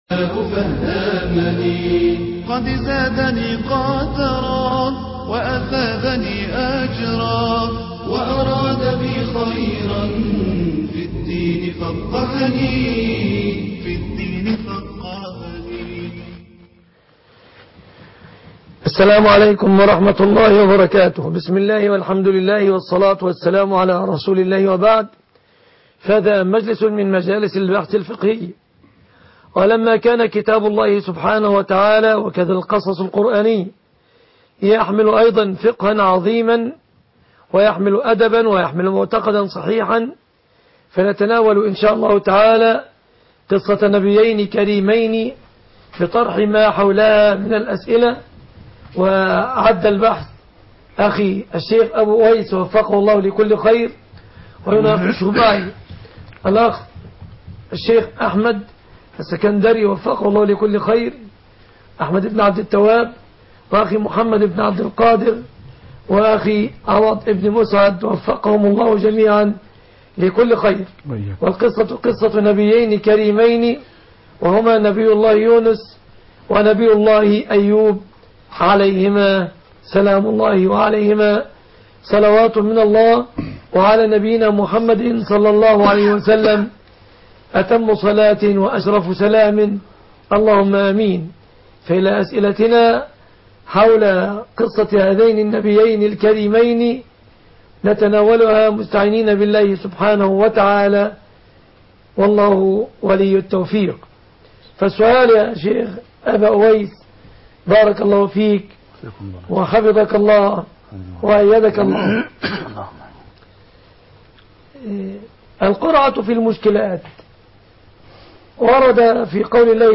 حول قصة سيدنا يونس و سيدنا ايوب سؤال وجواب